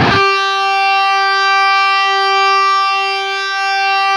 LEAD G 3 LP.wav